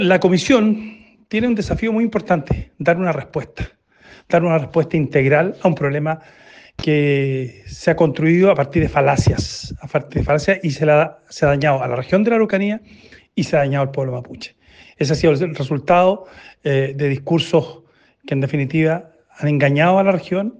En un tono similar se manifestó el diputado de Amarillos, Andrés Jouannet, indicando que la instancia debe entregar una respuesta integral a un problema construido – a su juicio – en base a “falacias”.